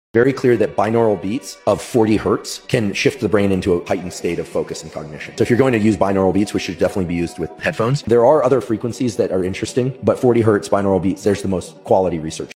Listening to 40 Hz binaural sound effects free download
Listening to 40 Hz binaural beats for focus can quickly help your brain get in the right mindset✨🧠